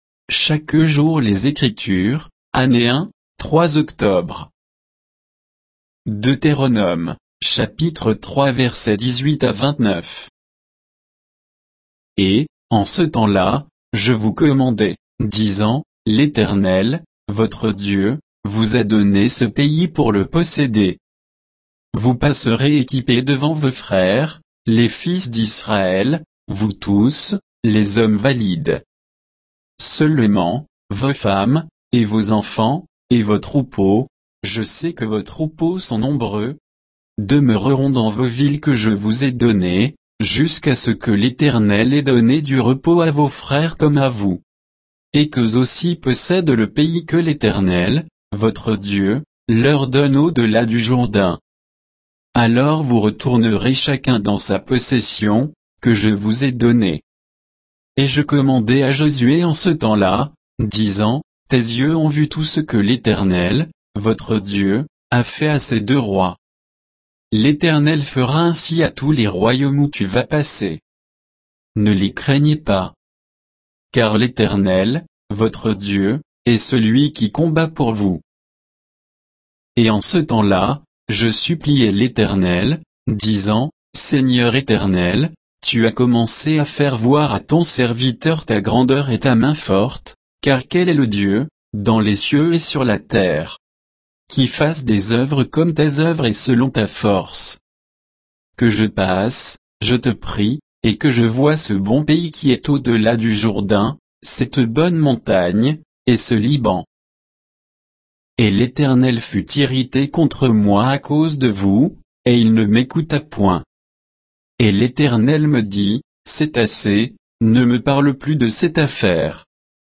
Méditation quoditienne de Chaque jour les Écritures sur Deutéronome 3